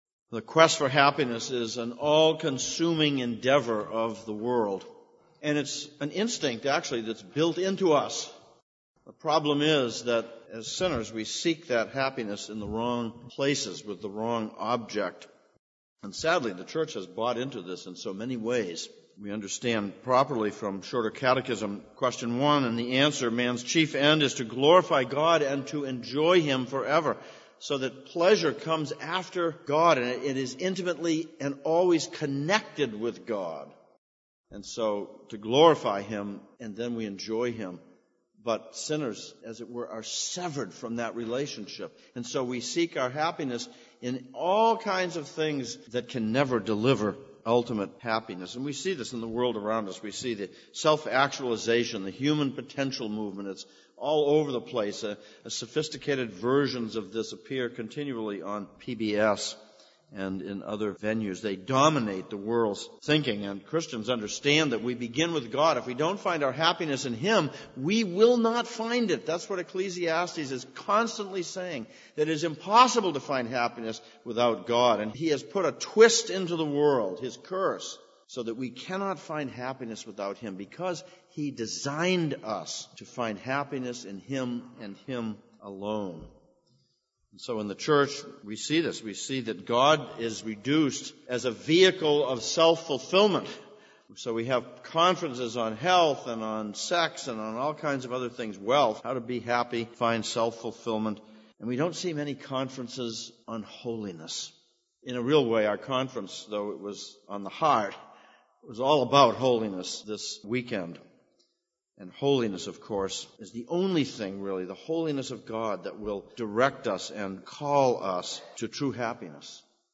Attributes of God Passage: Isaiah 6:1-8, Revelation 4:1-11 Service Type: Sunday Evening « First Things First 4.